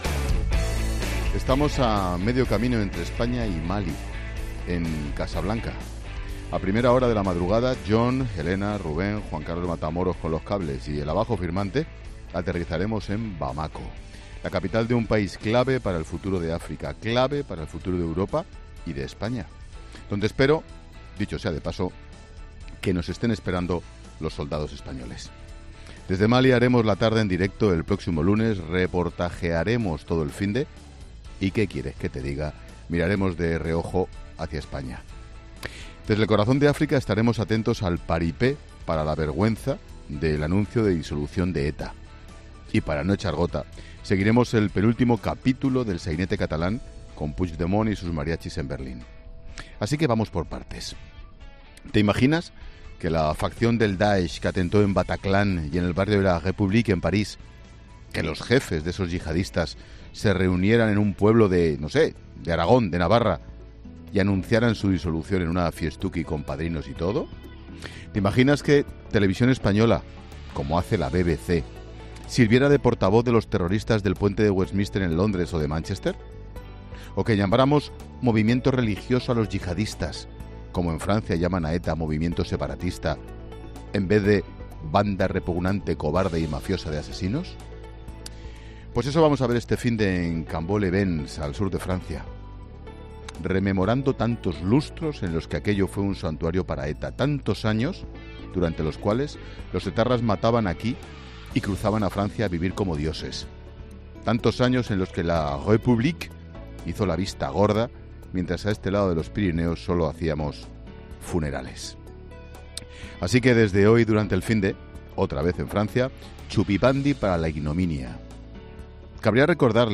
Monólogo de Expósito
El comentario de Ángel Expósito de las 16h desde Casablanca (Marruecos) antes de volar hasta Bamako (Mali).